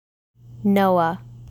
Noah (NO-ah)